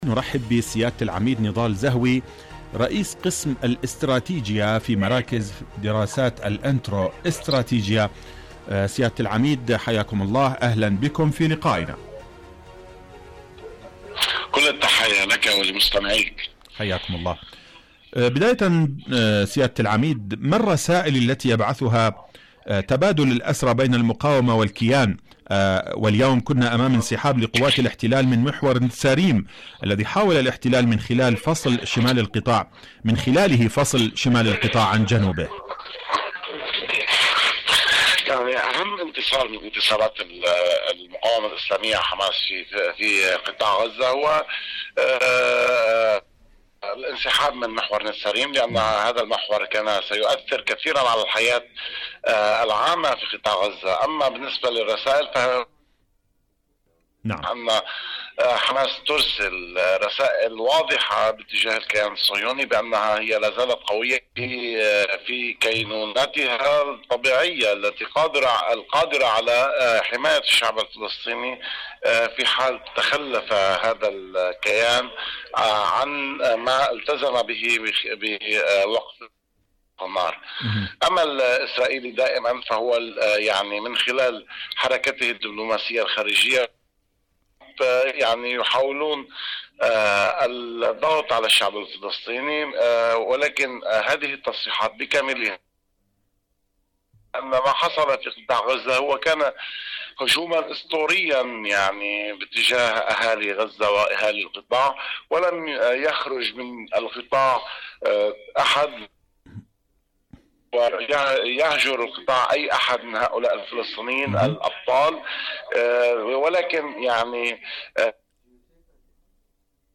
إذاعة طهران- فلسطين اليوم: مقابلة إذاعية